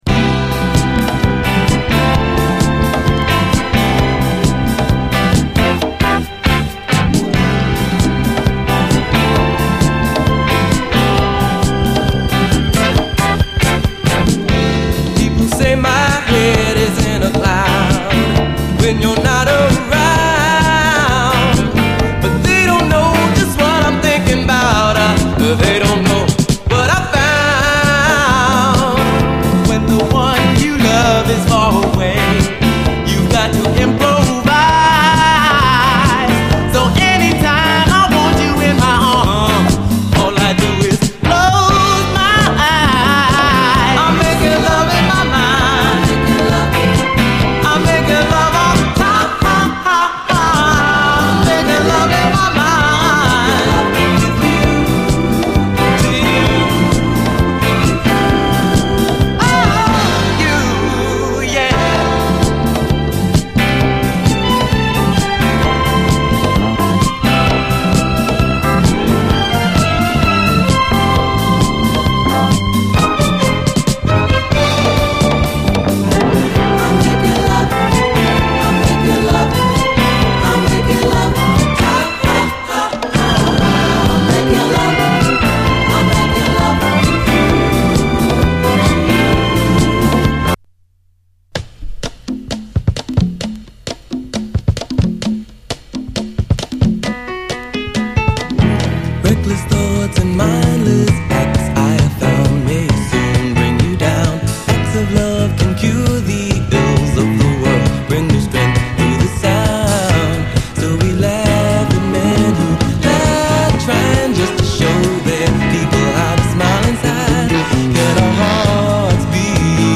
SOUL, 70's～ SOUL, DISCO
爽快モダン・ソウル・ダンサー
フィリー風味の爽快モダン・ソウル・ダンサー